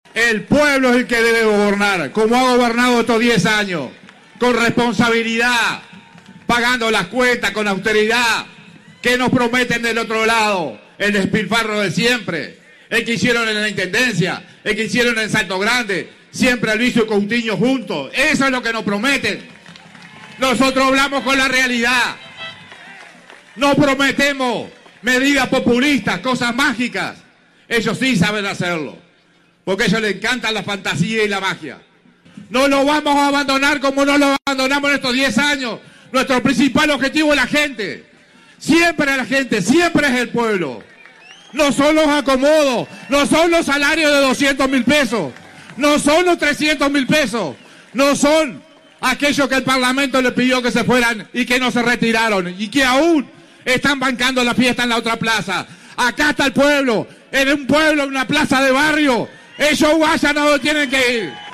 Multitudinario acto en la Plaza Estigarribia